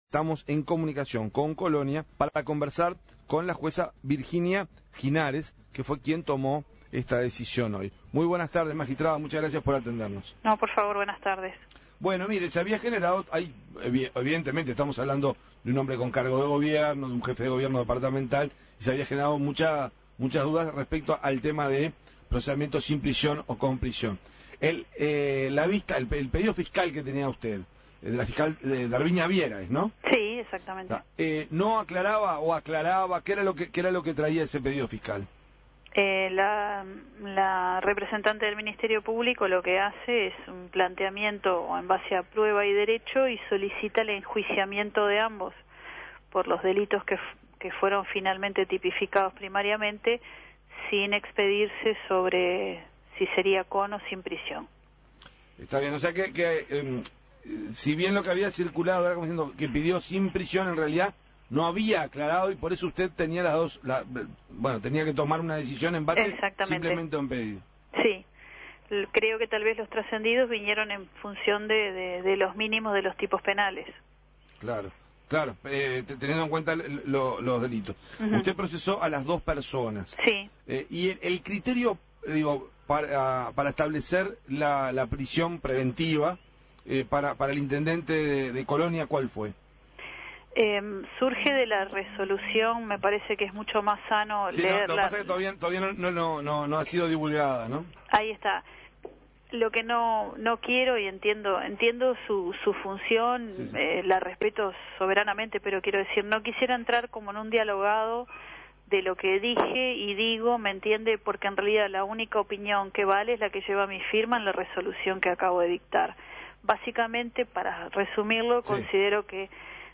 Escuche la entrevista a la jueza Virginia Ginares. El intendente de Colonia, Walter Zimmer, fue procesado con prisión por el delito de abuso continuado de funciones por la jueza Virginia Ginares.